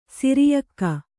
♪ siriyakka